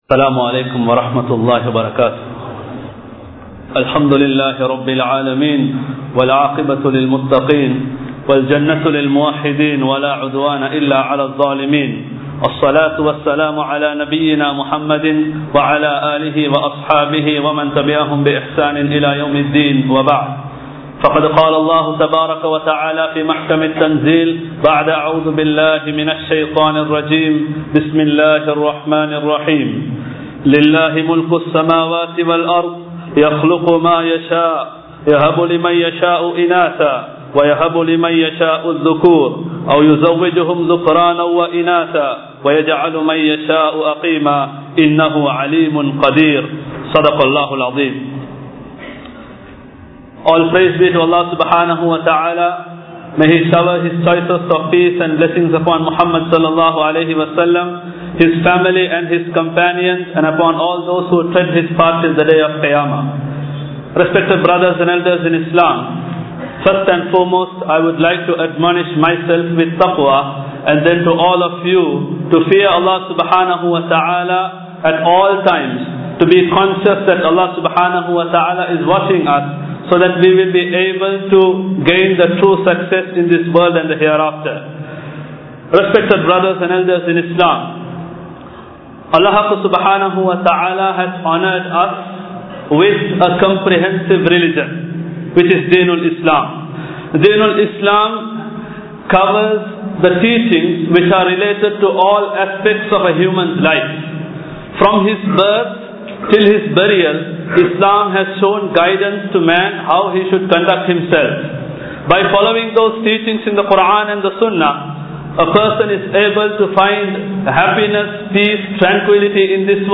Bringing up Children | Audio Bayans | All Ceylon Muslim Youth Community | Addalaichenai
Colombo 06, Kirulapana Thaqwa Jumua Masjith